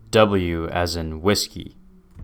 MycroftOS: Add local Mimic2 cache.